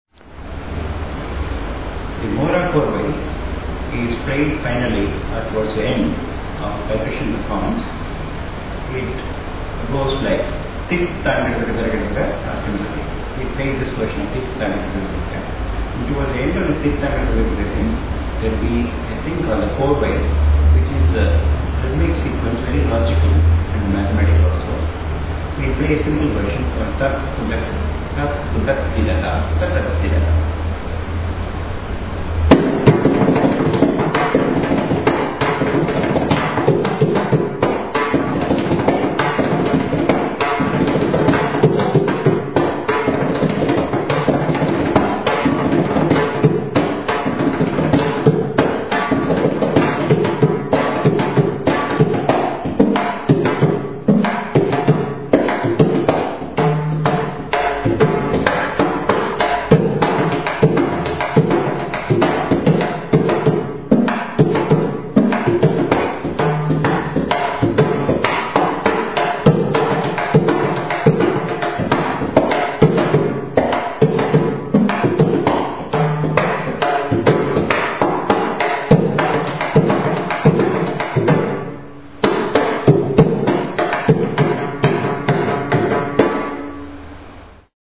Courses > Mridangam
The basic lessons generally contain drum syllables set to Adi talam, which is nothing but an 8-beat cycle of a steady rhythmic structure.
Audio Sample of a   Typical Mohra-Korvai